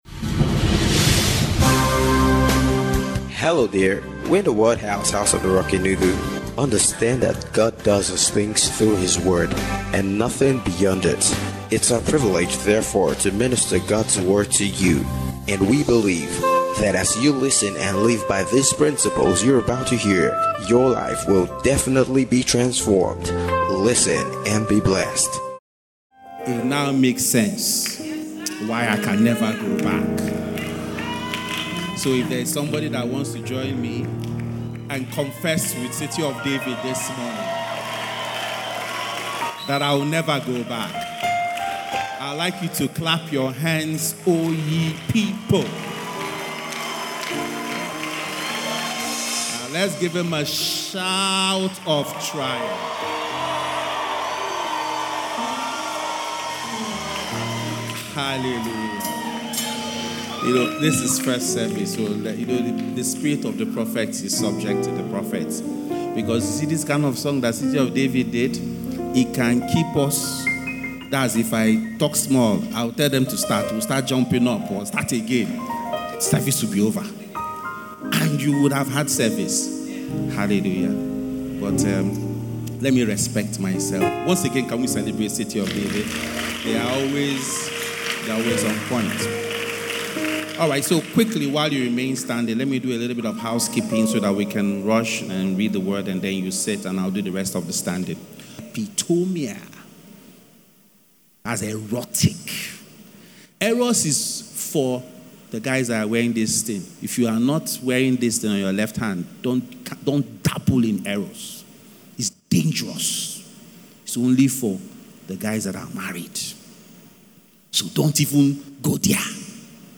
WHAT LOVE IS (FRESH DEW SERVICE)